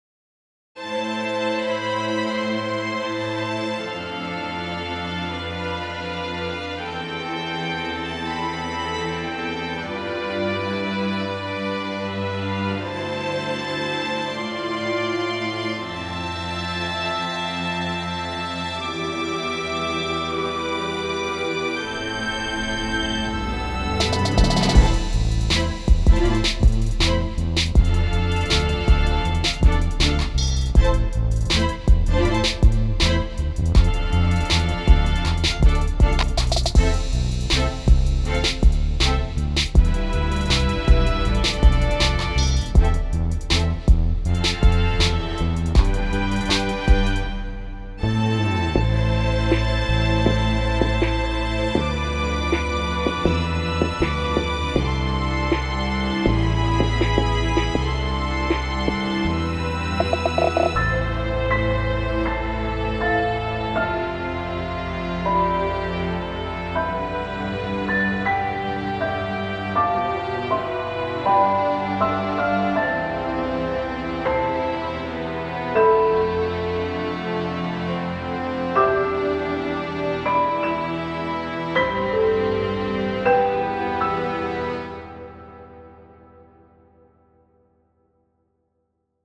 Classical / Hip-hop / Commercial